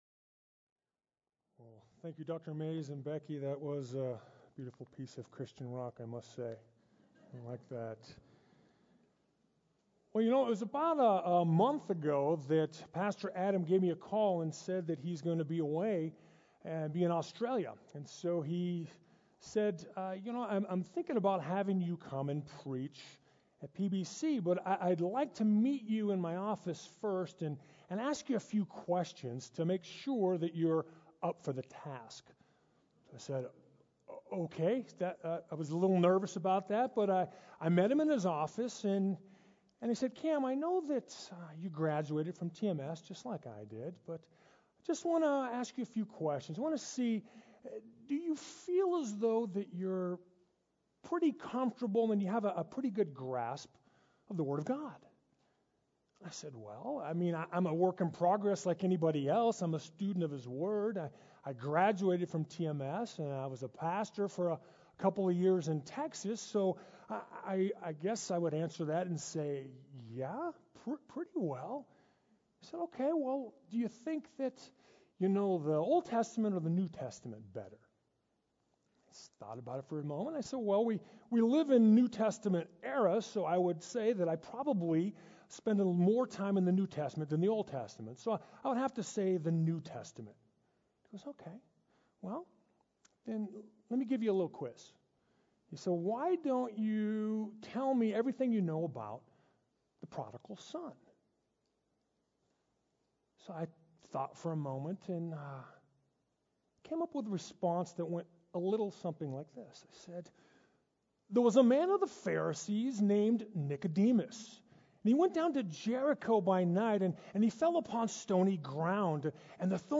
Sermon Detail | Placerita Bible Church